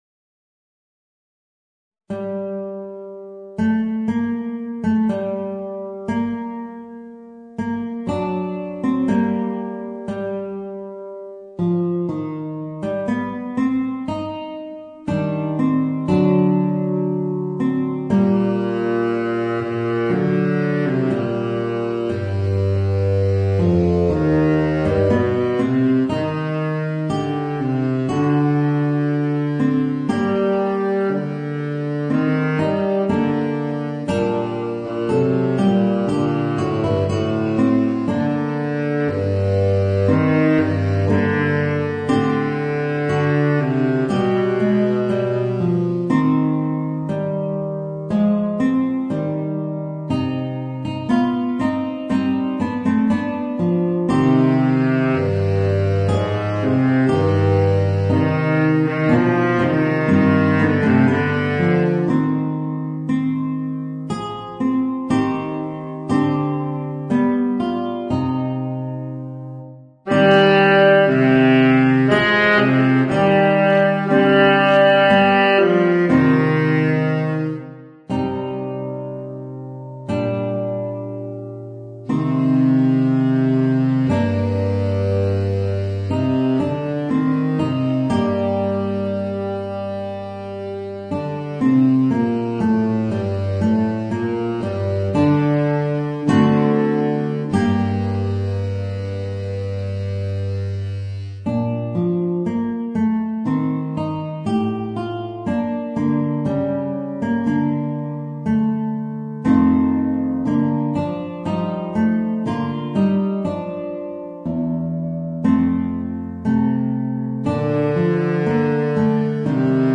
Voicing: Baritone Saxophone and Guitar